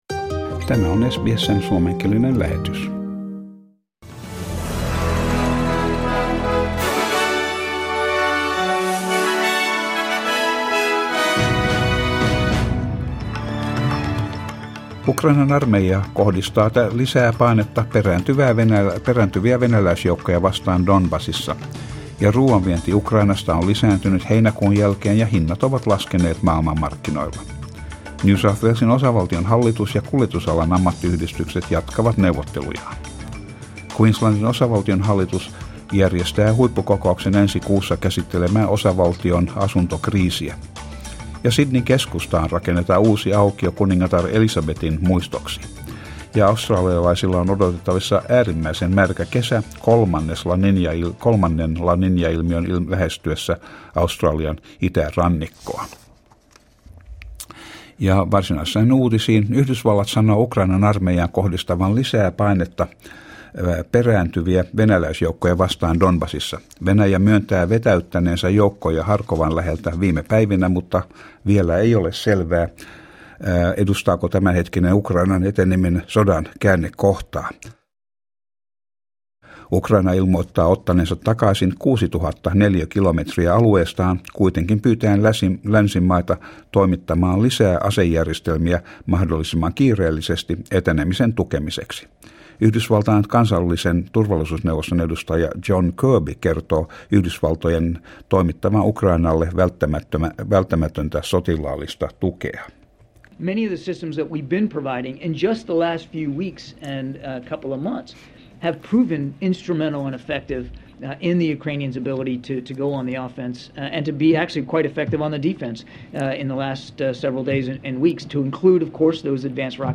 Uutiset ja sää 14.9.22